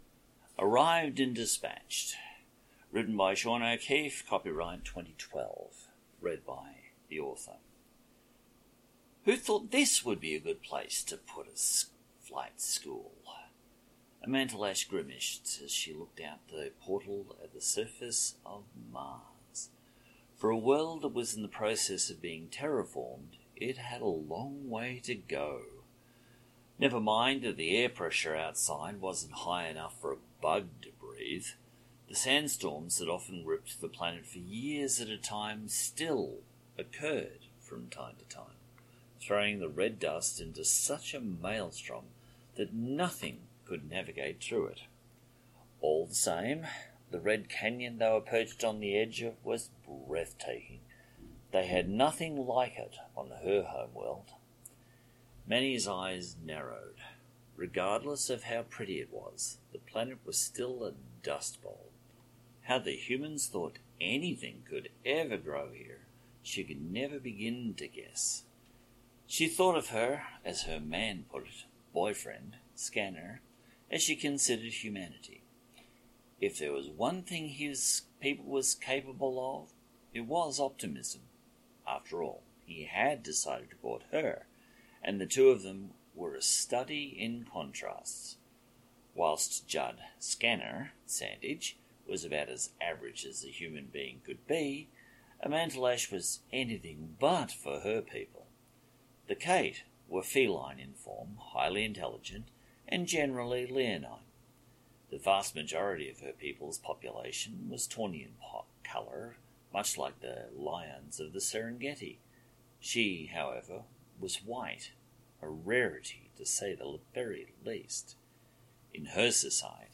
Audio Books/Drama